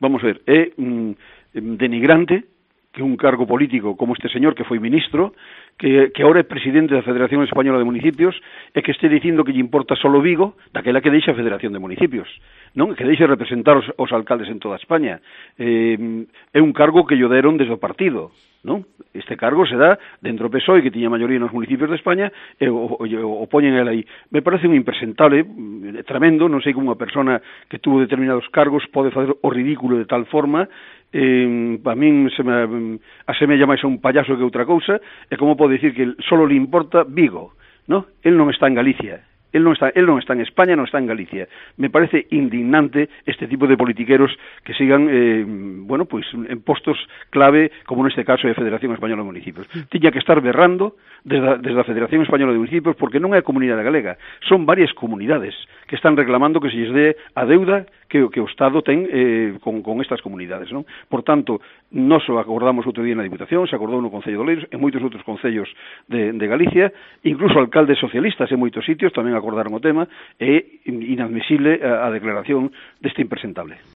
En COPE Coruña, lo invita a abandonar la FEMP si no defiende la devolución del dinero que debe el Estado
“Es denigrante que un cargo político que fue ministro y que ahora es presidente de la Federación Española de Municipios y Provincias diga que solo le importa Vigo”, comentaba indignado Seoane en la emisora.